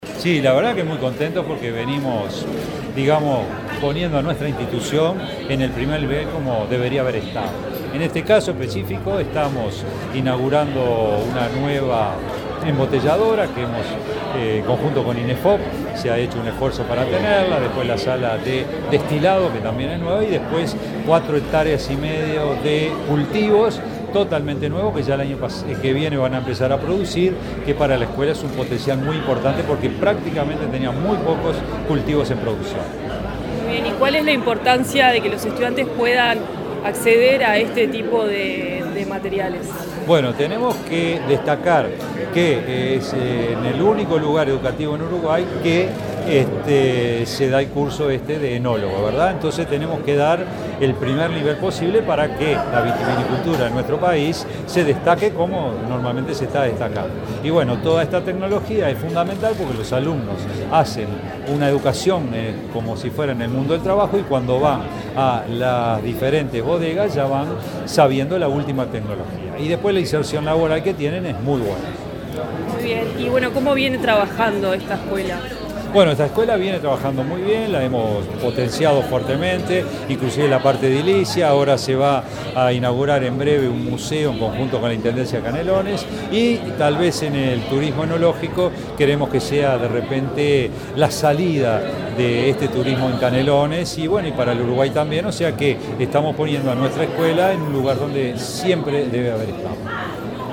Declaraciones del director general de UTU
El director general de la UTU, Juan Pereyra, dialogó con Comunicación Presidencial durante la inauguración de una sala de destilado y una